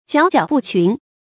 矯矯不群 注音： ㄐㄧㄠˇ ㄐㄧㄠˇ ㄅㄨˋ ㄑㄩㄣˊ 讀音讀法： 意思解釋： 矯矯：翹然出眾的樣子；群：與眾不同。